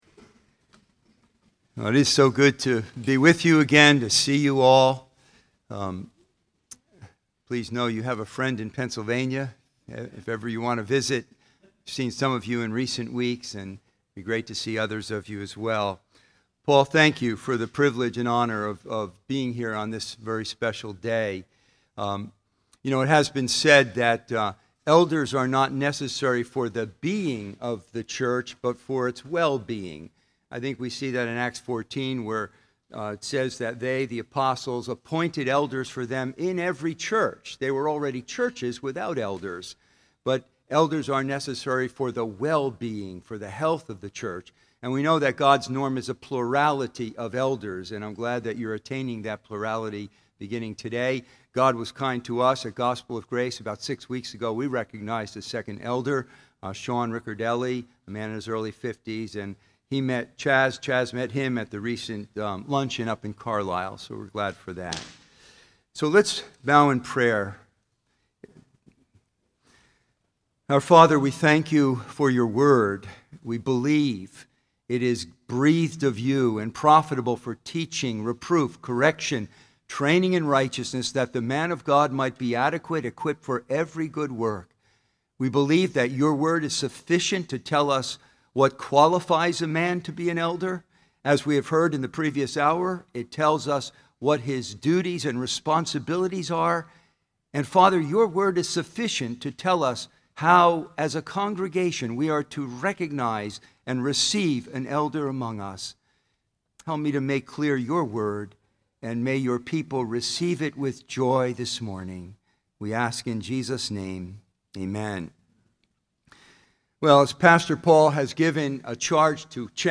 Sermons – Redeemer Community Church